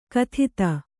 ♪ kathita